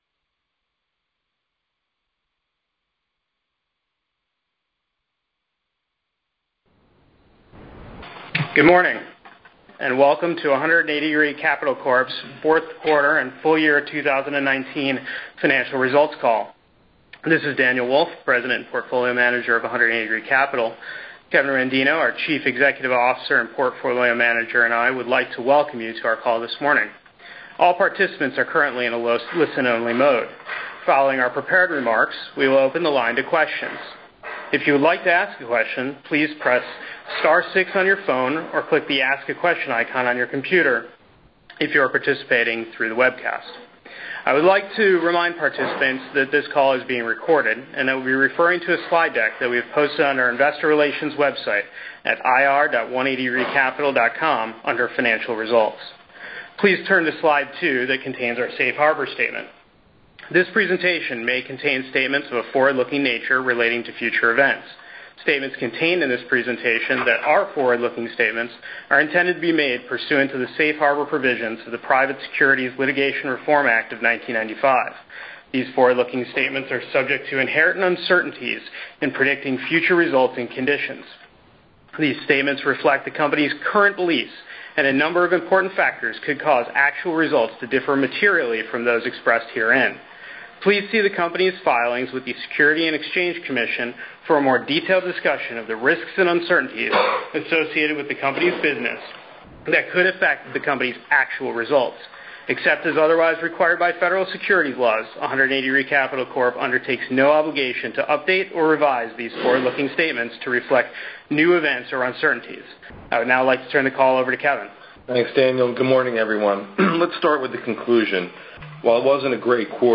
Call Recording